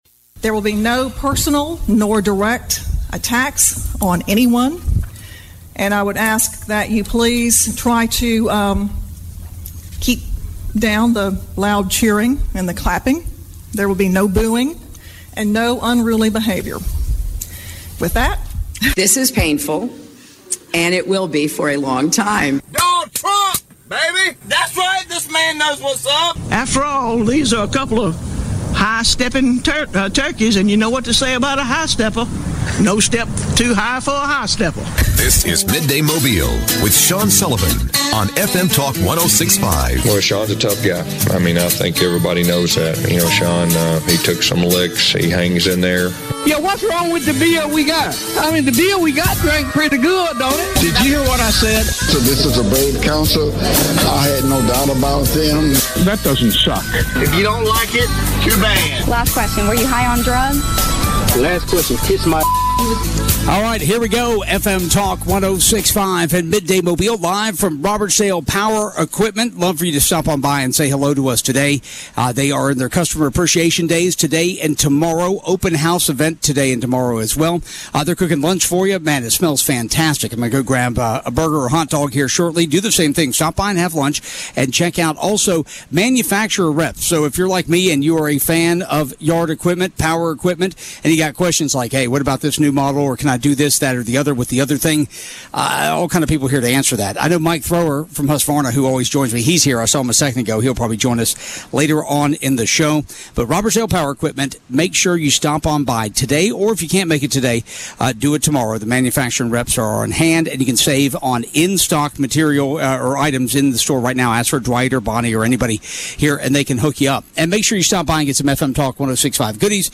Midday Mobile Wednesday 2-23-22_Broadcasting from Robertsdale Power Equipment